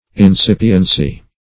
Incipience \In*cip"i*ence\, Incipiency \In*cip"i*en*cy\, n. [L.